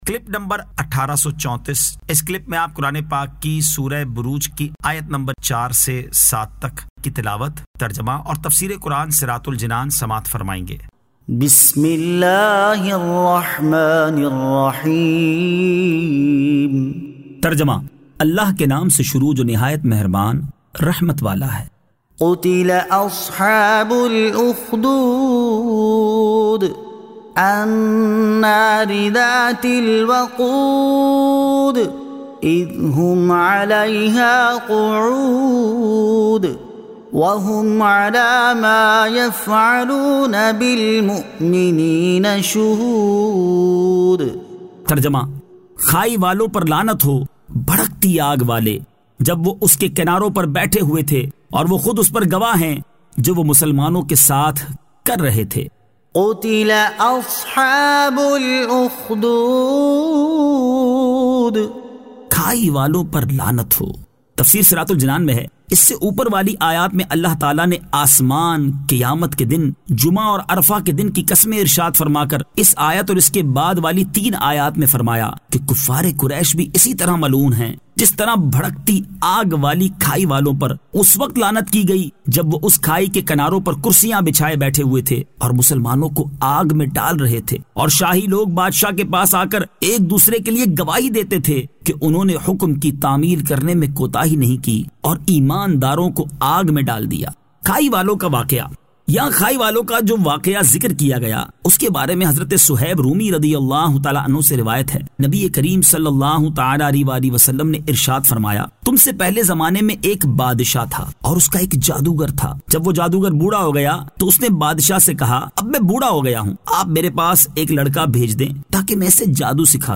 Surah Al-Burooj 04 To 07 Tilawat , Tarjama , Tafseer